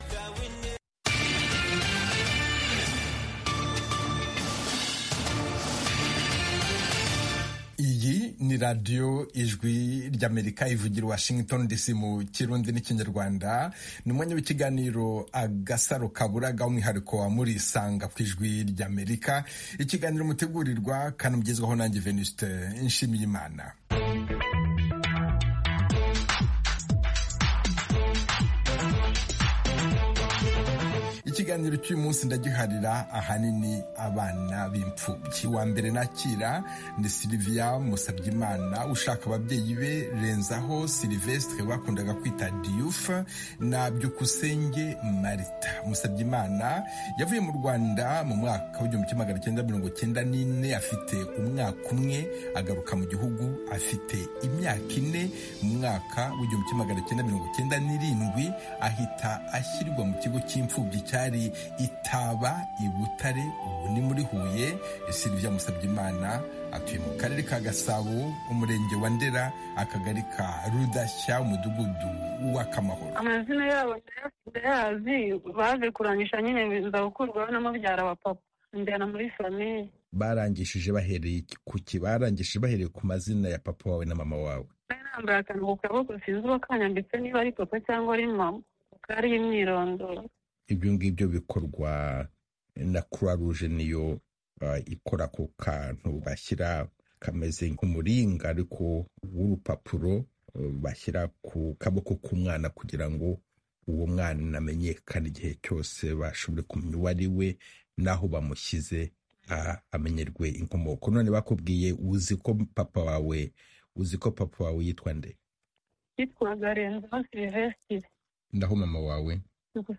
Murisanga itumira umutumirwa, cyangwa abatumirwa kugirango baganira n'abakunzi ba Radiyo Ijwi ry'Amerika. Aha duha ijambo abantu bivufa kuganira n'abatumirwa bacu, batanga ibisobanuro ku bibazo binyuranye bireba ubuzima b'abantu.